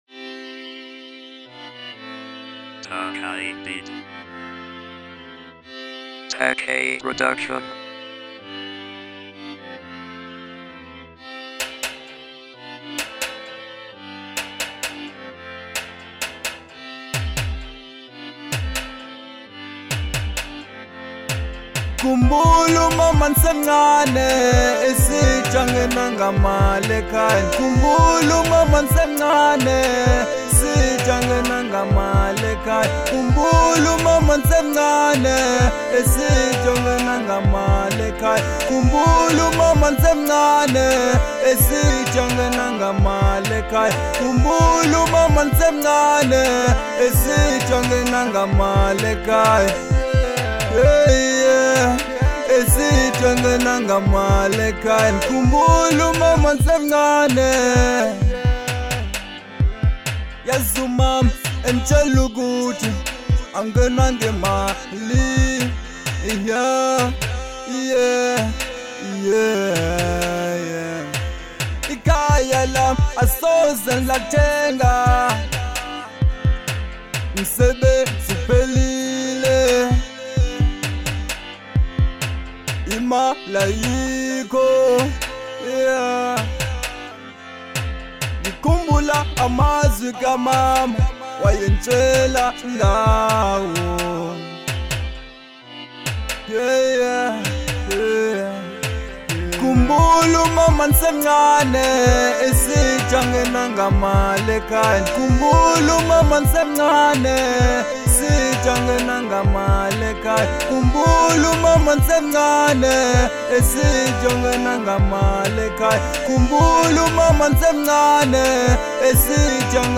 02:40 Genre : Hip Hop Size